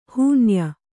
♪ hūnya